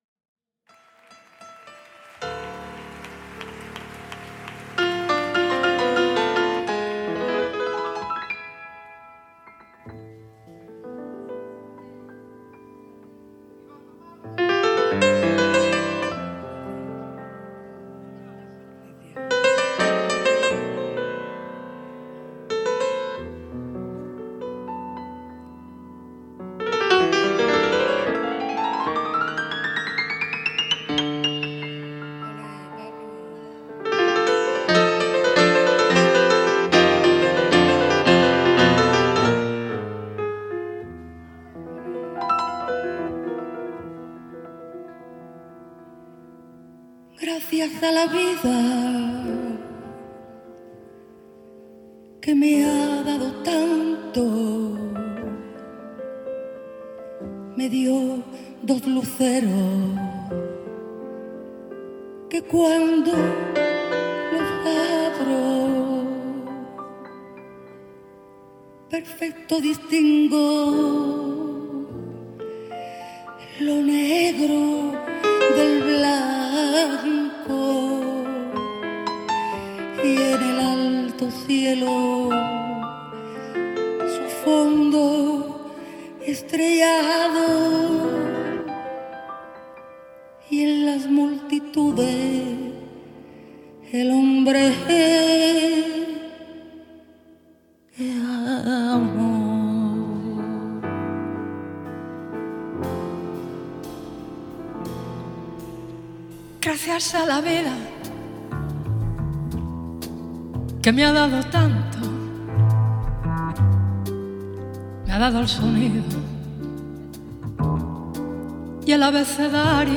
En Directo
piano
contrebasse
batterie
guitare
chœurs